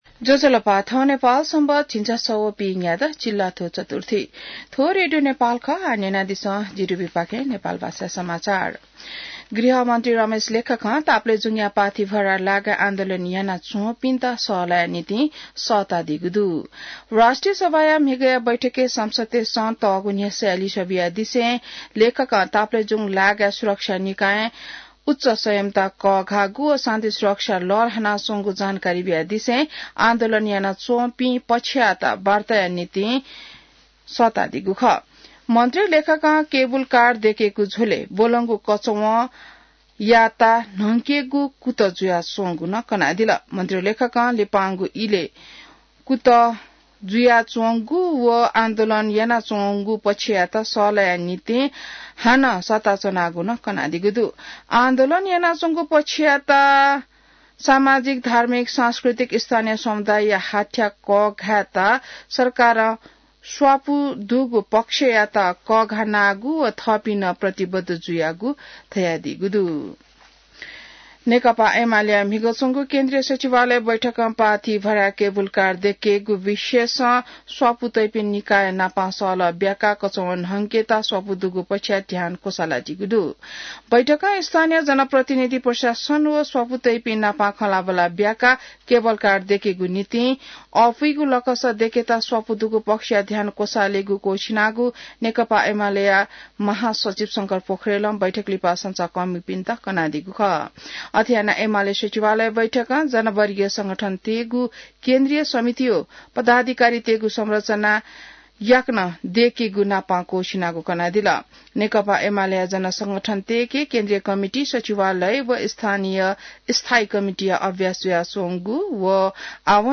नेपाल भाषामा समाचार : २० फागुन , २०८१